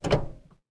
car-steer-3.ogg